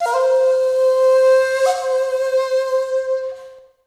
D3FLUTE83#05.wav